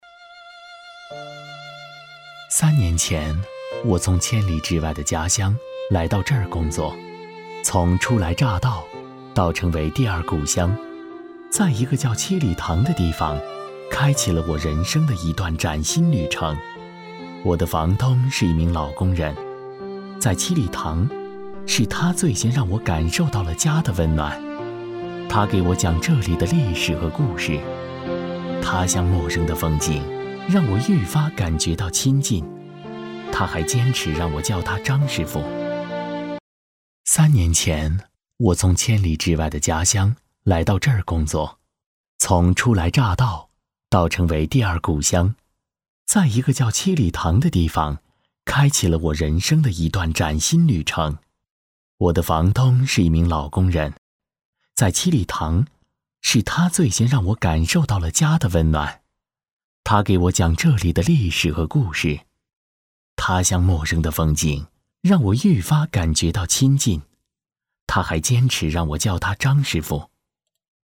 男63 人物自述-- 三年前，我从千.mp3